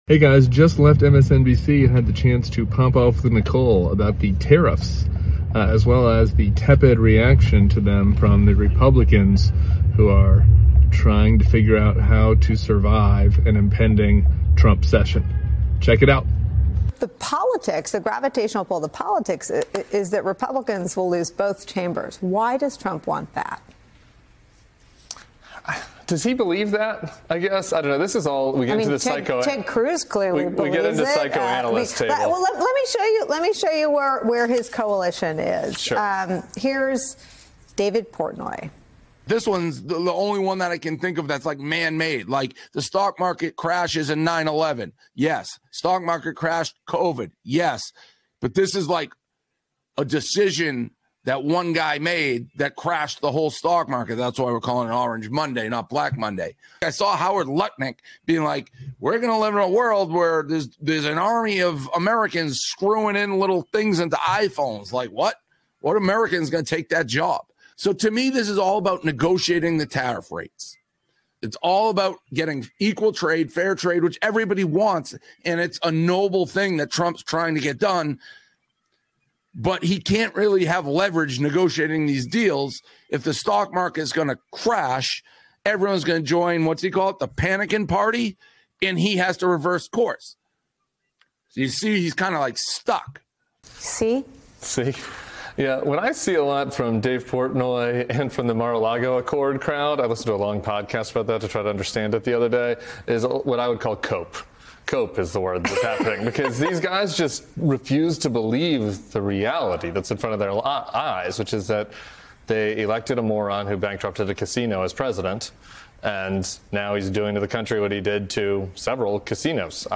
Tim Miller joins Nicolle Wallace on MSNBC Deadline: White House to discuss Donald Trump's tariffs tanking the economy and sending us to a recession as Republican lawmakers do absolutely nothing.